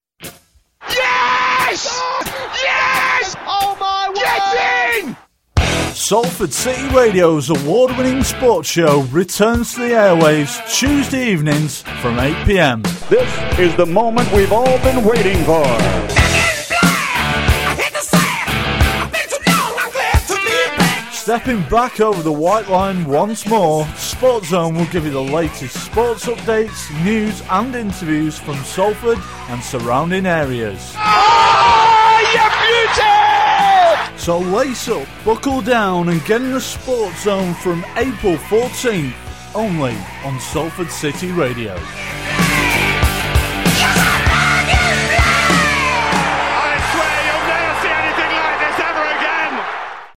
Clips from BBC, Talksport and Sky Sports.